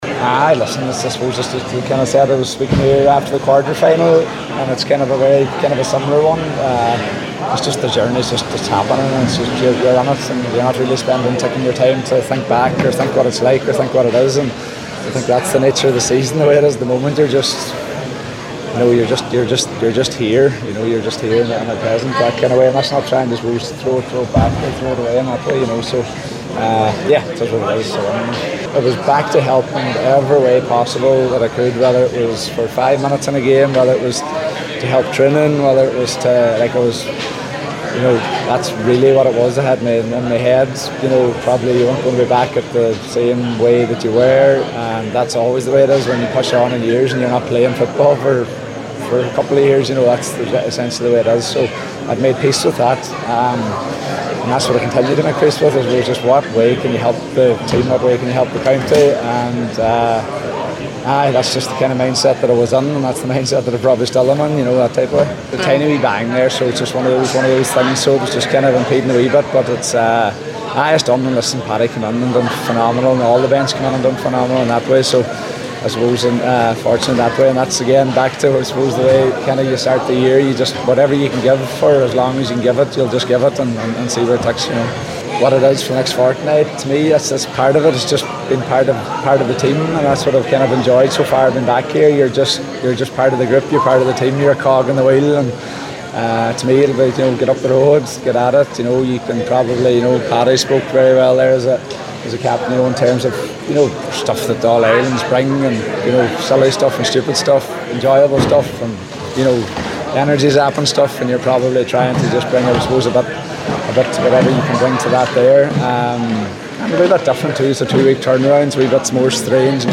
Murphy spoke to the assembled media after today’s 20-point semi-final victory and described what it means for him to represent Donegal.